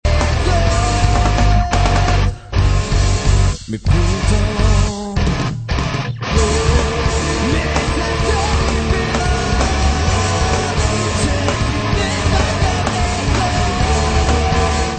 neo métal